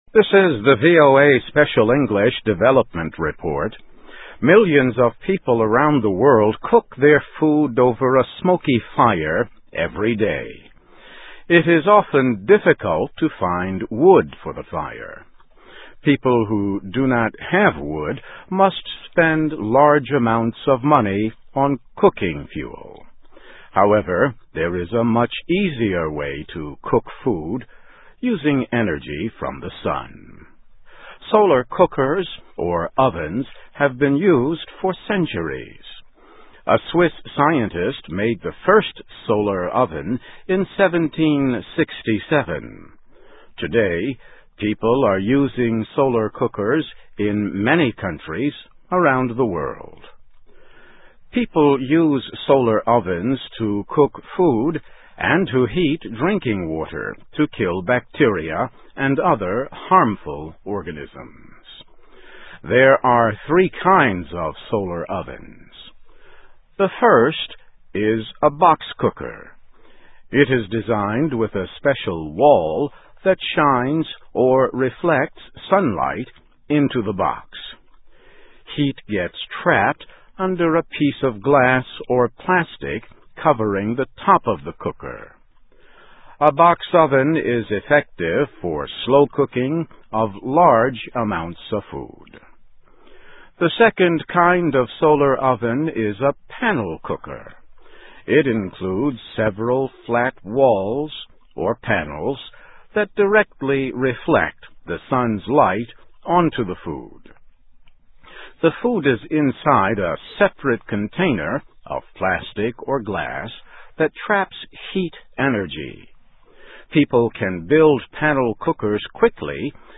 Topic: Learn about three kinds of solar ovens. Transcript of radio broadcast.